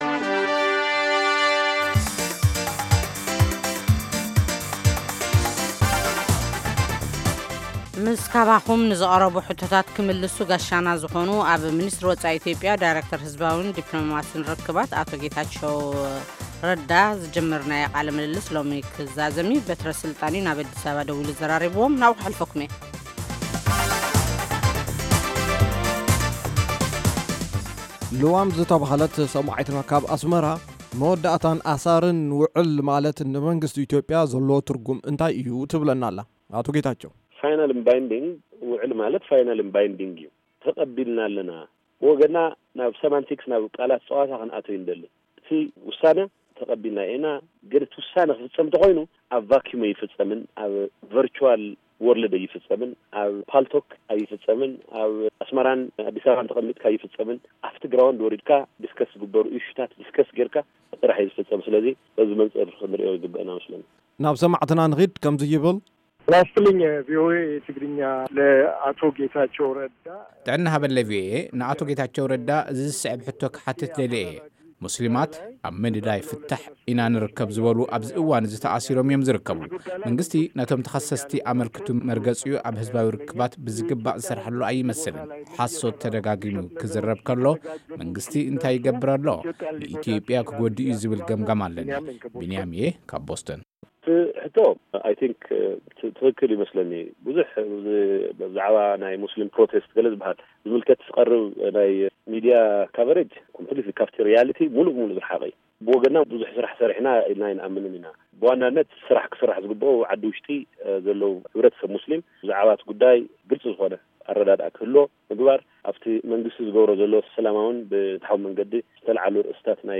ኣቶ ጌታቸው ረዳ ኣብ ናይ ሎሚ ቃለ-ምልልስ ምስ ቪኦኤ ጉዳይ ምስ ገለ ሙስሊማት ዝተራኣየ ምስሕሓብን መንግስቲ ዝሓዘሉ ኣገባብ ህዝባዊ ርክባትን፣ጉዳይ ምስሕሓብ ዶብ ምስ ኤርትራን ኣፈታትሕኡን፣ከምኡውን ሕቶ ግድብ ኣባይን ካብ ፖለቲካዊያን ግብፂ ዝቃላሕ መፈርሕን መዘራረቢ ኮይኖም‘ለው። መንግስቲ ኢትዮዽያ ኣብ ዓለም-ለኸ ሚድያ ንኩነታት ምስ ’ኣናዳዪ ሓቂ’ ኢሉ ንርእሱ ዝገልፅ ኣካል ዘጓነፎ ፀገም ኣብ ምብራህ እኹል ስራሕ ፈፂሙ‘ዩ ዝብል እምነት የብለይን ኢሎም...